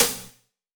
drum-hitwhistle.wav